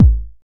Kick OS 01.wav